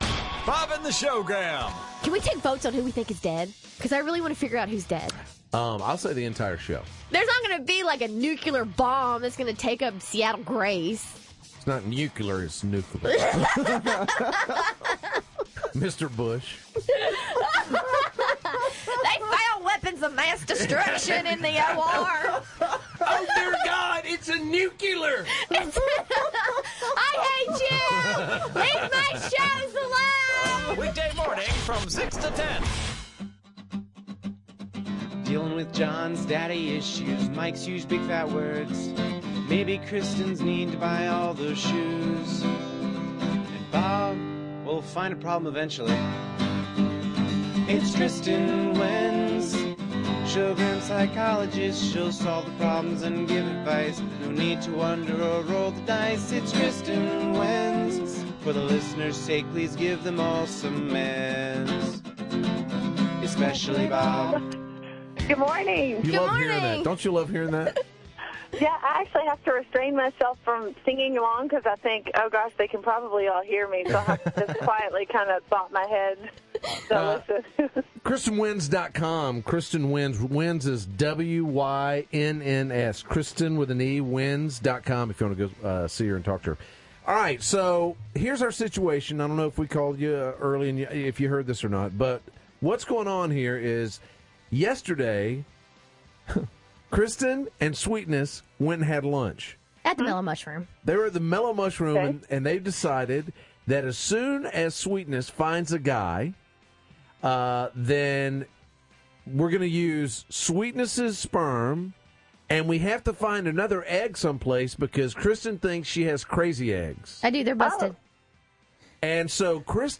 You can listen to the archived on-air mp3 segments below.